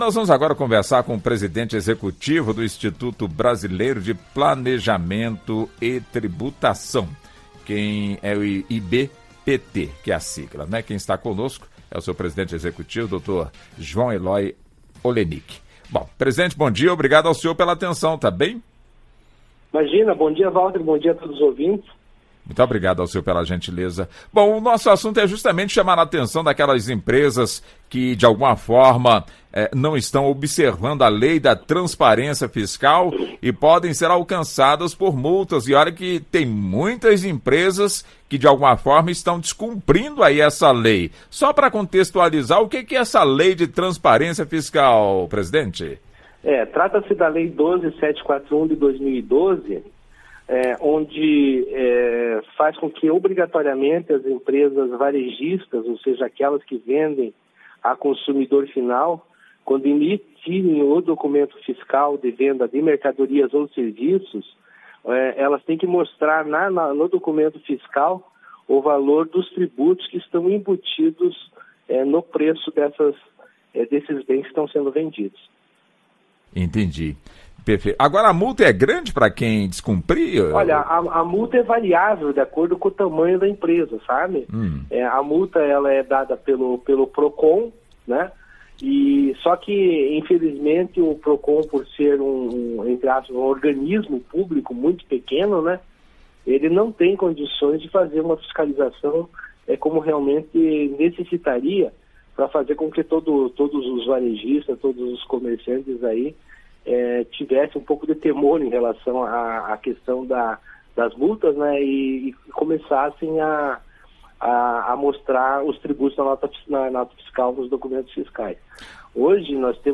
Ele explica o que � Lei da Transpar�ncia Fiscal Saiba mais sobre o assunto, ouvindo a entrevista na �ntegra no player acima.